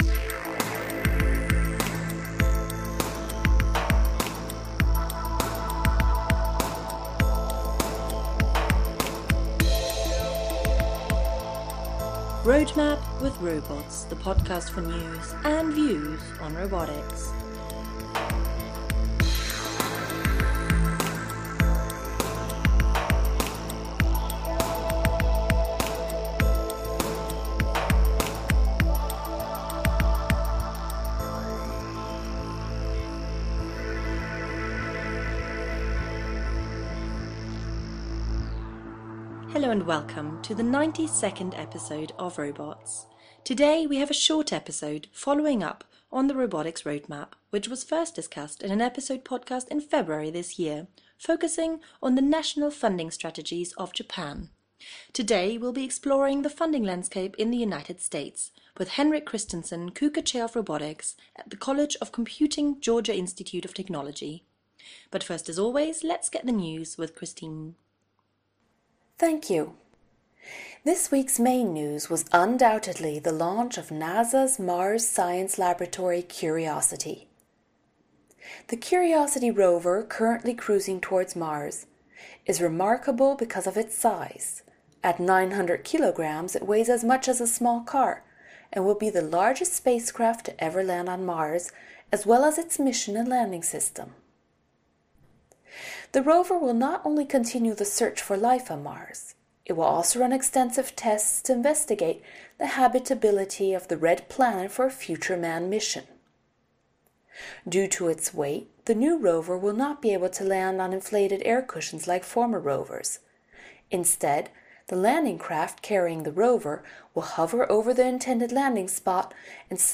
The interview was recorded at the International Conference on Human-Robot Interactions in Switzerland this spring.